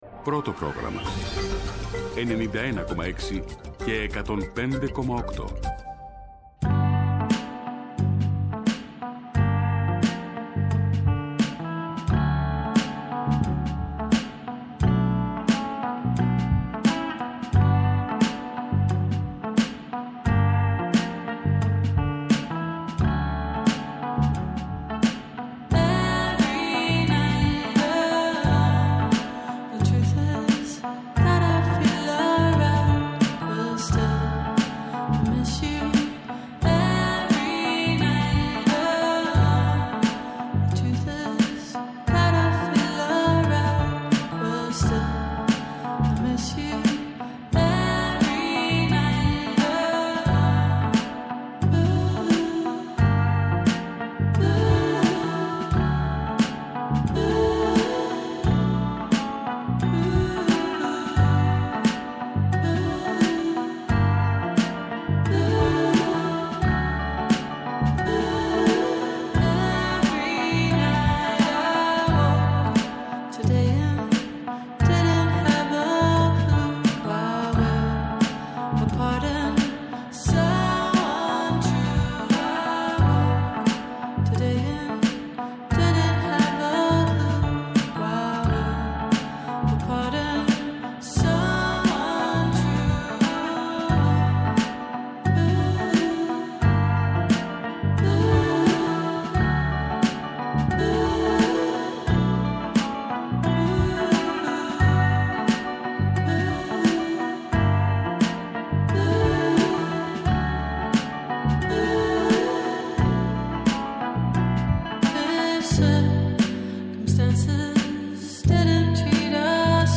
Εκτακτη ενημερωτική εκπομπή